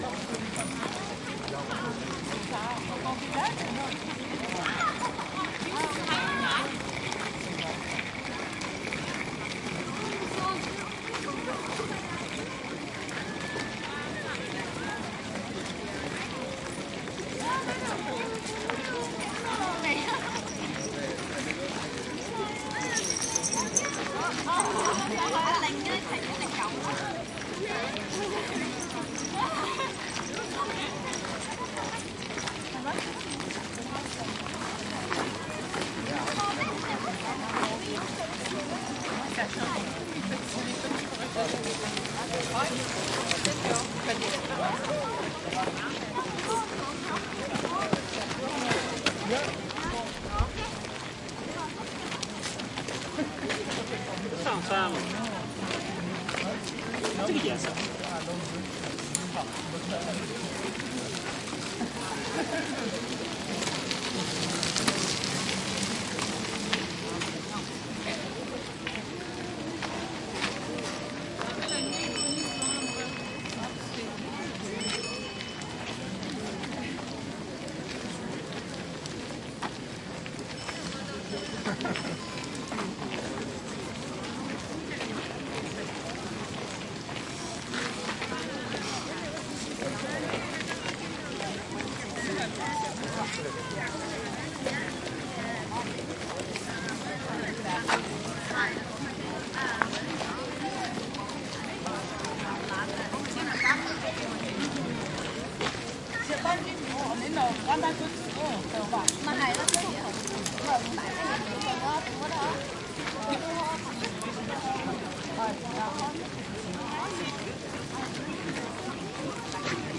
随机 "的脚步声，中等规模的人群和砾石公园，良好的细节。
描述：脚步声中等人群外面碎石公园好detail.flac
Tag: 运动 蒙特利尔 脚步声 地铁 步骤 INT 地铁 人群 加拿大